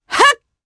Demia-Vox_Jump_jp_b.wav